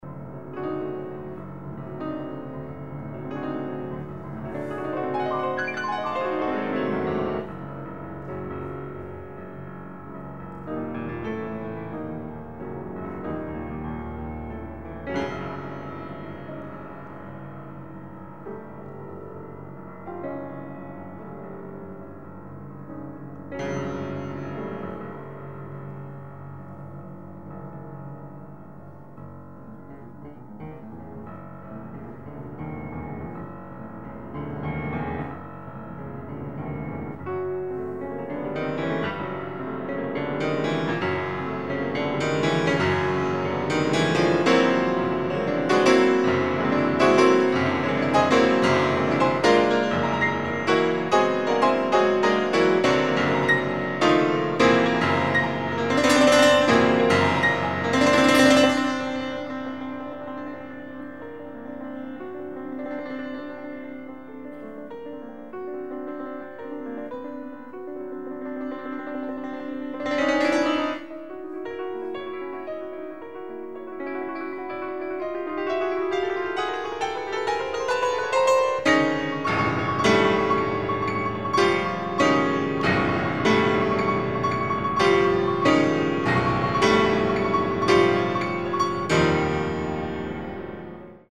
Pianist
Recorded May 21, 1981 in the Ed Landreth Hall, Texas Christian University, Fort Worth, Texas
Sonatas (Piano)
performed music